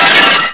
glass4.wav